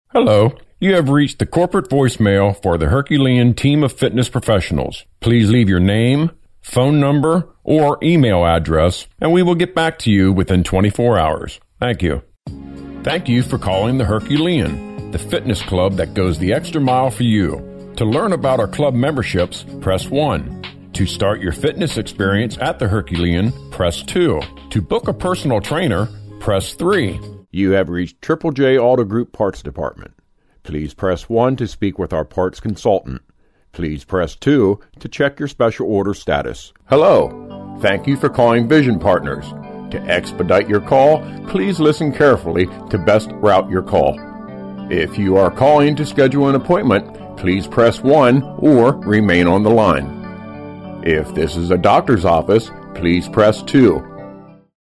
Strong yet pleasing voice, warm, humorous, sincere, professional, believable.
Smooth,warm voice for narration.
Sprechprobe: Sonstiges (Muttersprache):